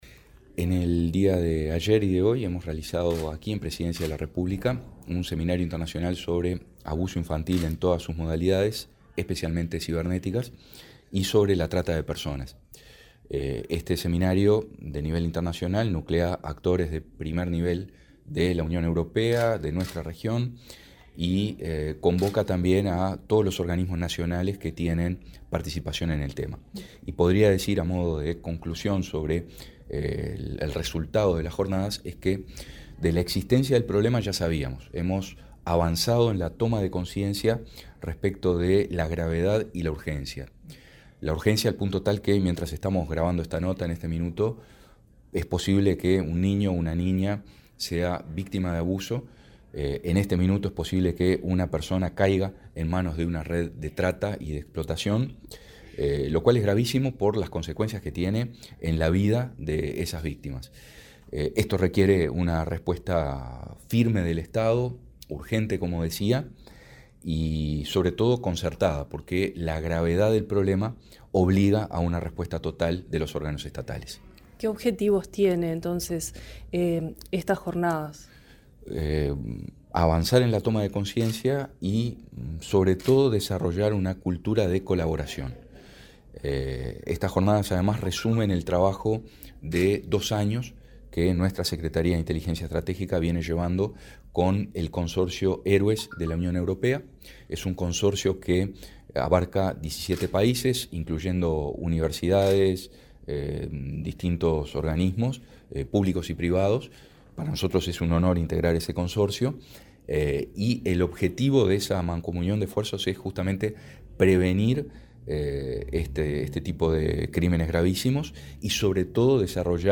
Entrevista al director de la Secretaría de Inteligencia Estratégica de Estado, Álvaro Garcé